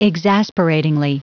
Prononciation du mot exasperatingly en anglais (fichier audio)
Prononciation du mot : exasperatingly